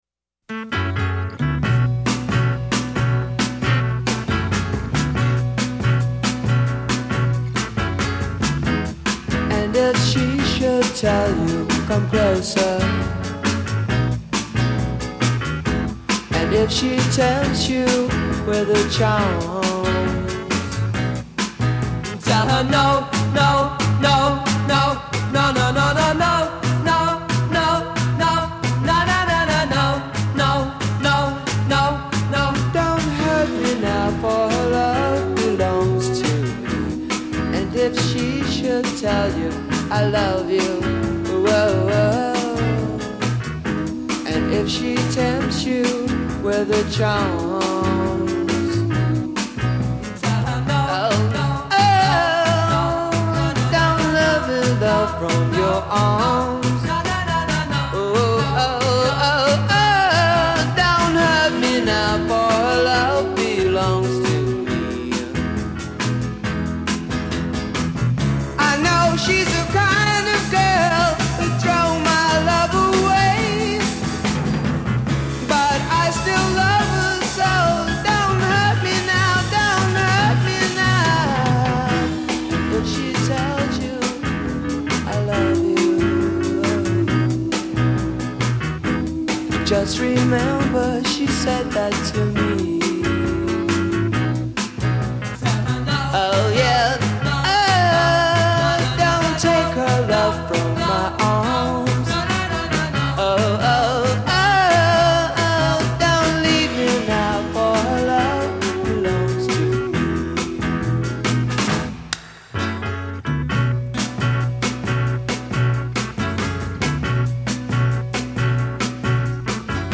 Intro 0:00 4 Piano/guitar/bass/drum pattern of 4's and 3's
A Verse 0: 8 Solo vocal over ensemble a
Refrain : 8 Unison statement of hook. b
A Verse : 8 As in verse above with sustaining harmonies. d
B Chorus : 8 Texture change: Voice doubled by piano. e